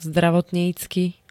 Zvukové nahrávky niektorých slov
wdaa-zdravotnicky.ogg